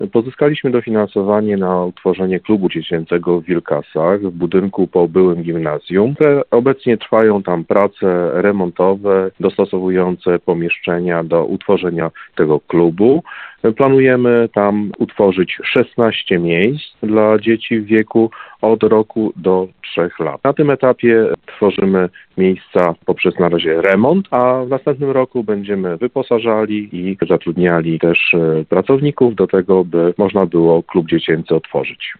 Jak poinformował Radio 5 wójt gminy Giżycko Marek Jasudowicz, w tej chwili trwa remont pomieszczeń.